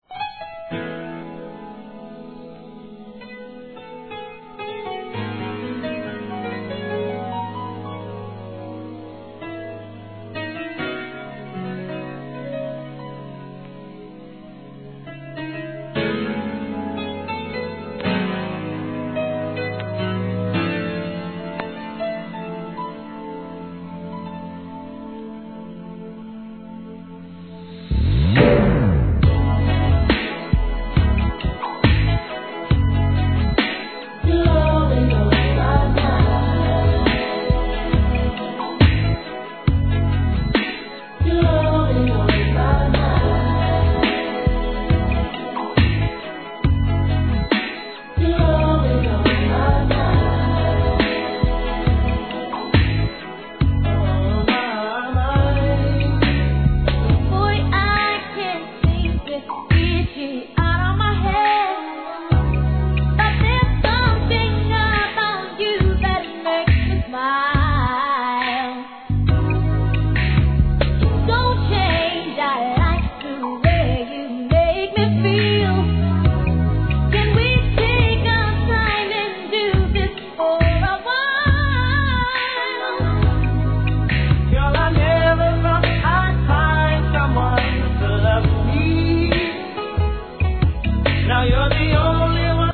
1. HIP HOP/R&B
1993年、清涼感溢れるヴォーカルワークが載るミディアム・ナンバー♪